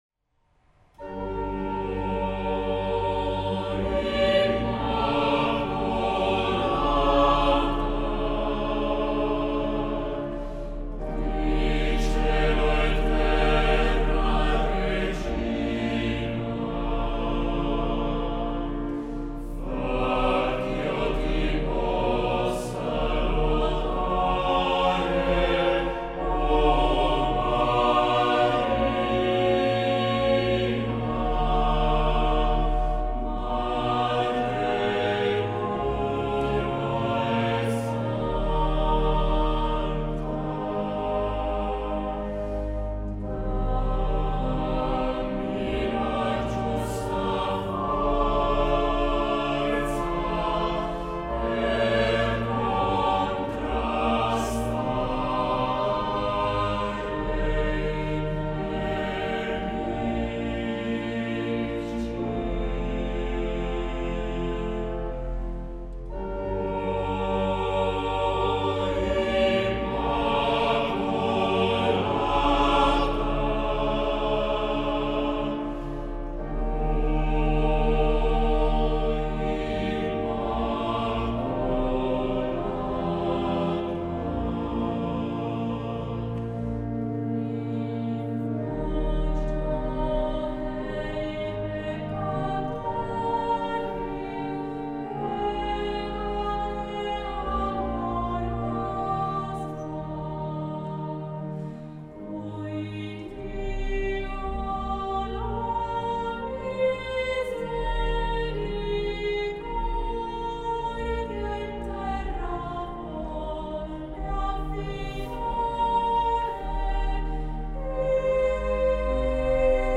coro a 4 voci miste e organo